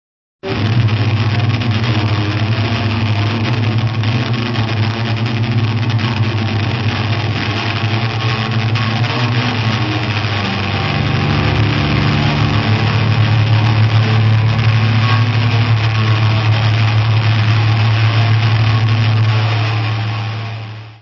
: stereo; 12 cm
Área:  Novas Linguagens Musicais